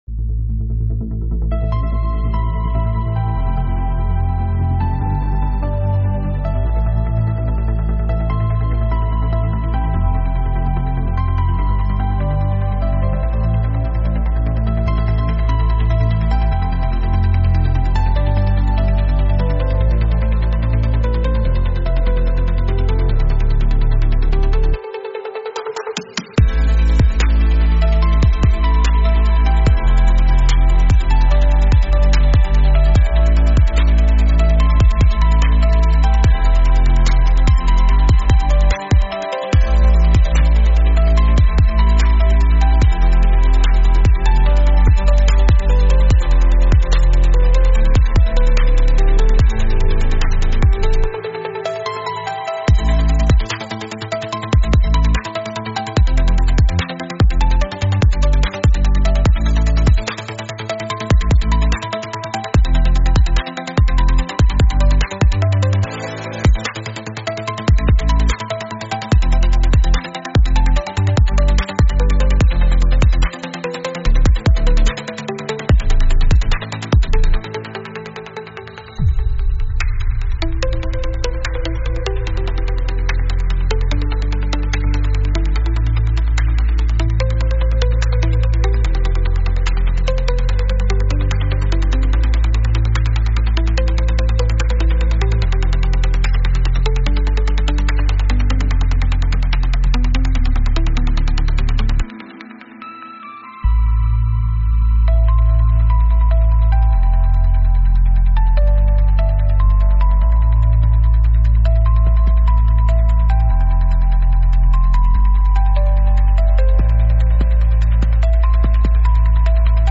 Instrumental - Real Liberty Media DOT xyz